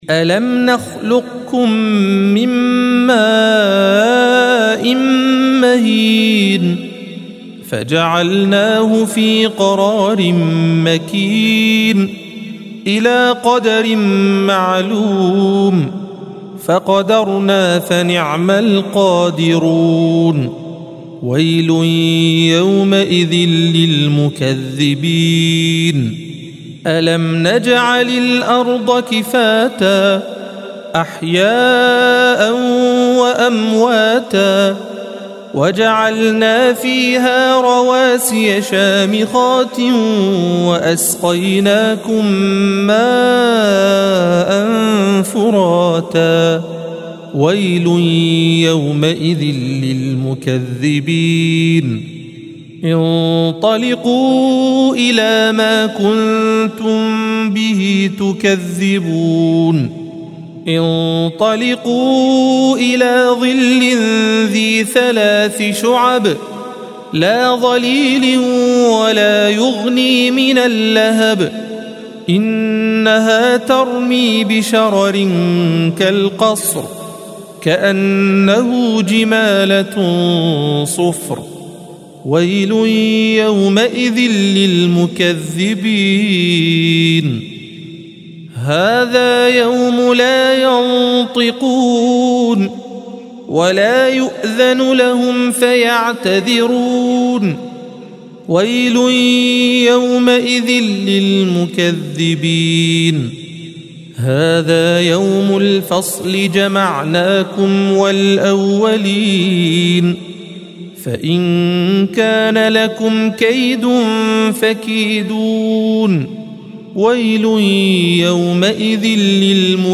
الصفحة 581 - القارئ